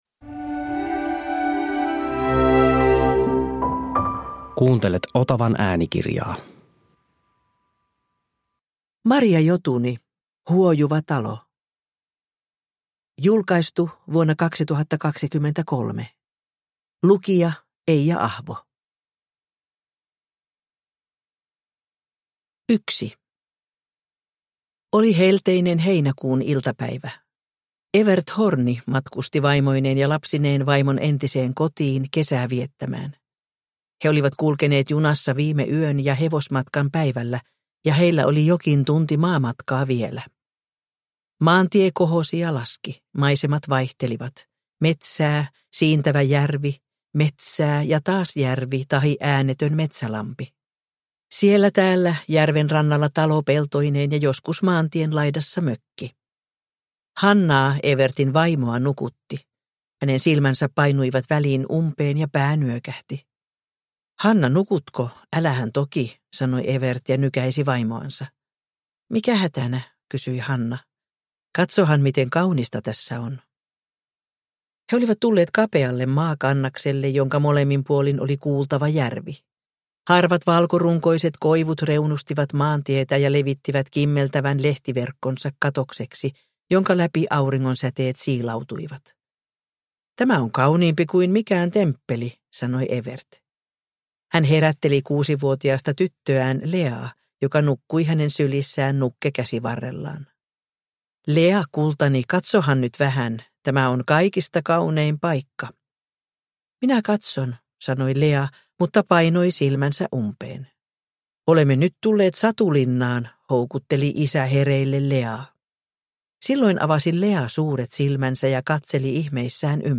Huojuva talo – Ljudbok – Laddas ner